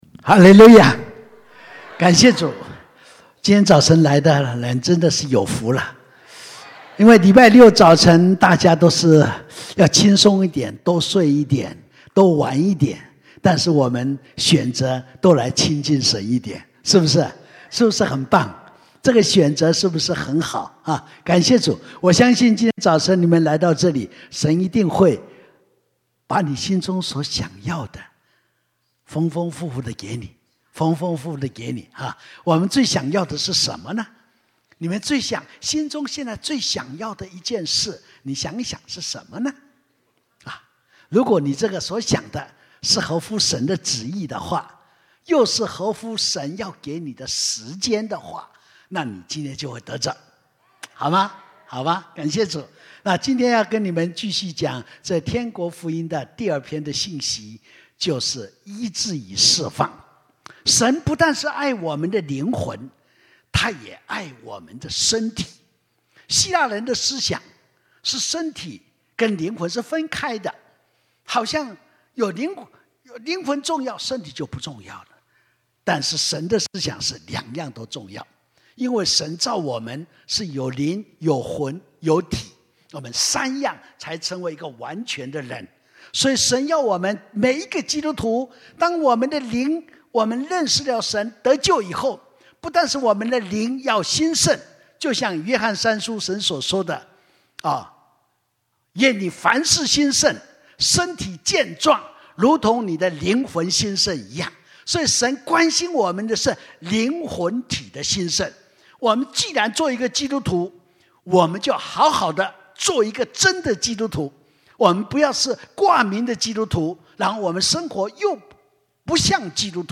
天国的福音布道大会 (二)